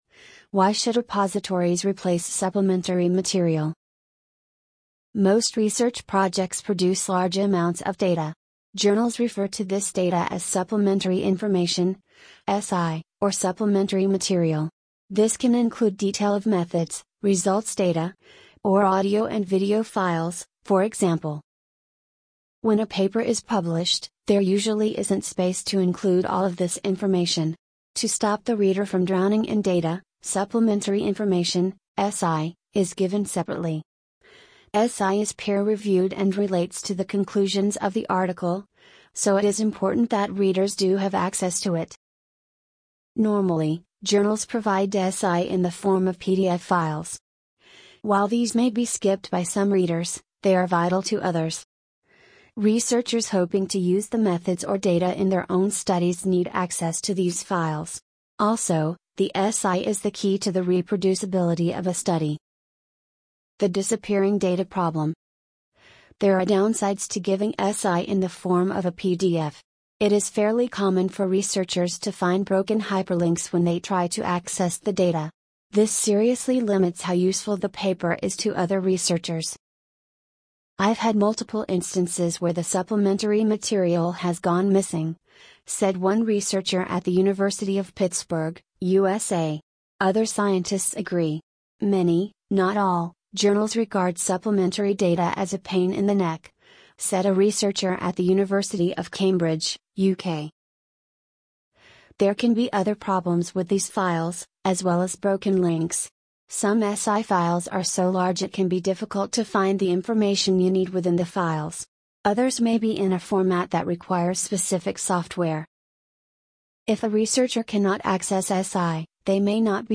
amazon_polly_276.mp3